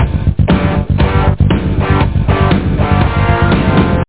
home *** CD-ROM | disk | FTP | other *** search / PSION CD 2 / PsionCDVol2.iso / Wavs / E-GuitarSound ( .mp3 ) < prev next > Psion Voice | 1998-08-27 | 33KB | 1 channel | 8,000 sample rate | 4 seconds
E-GuitarSound.mp3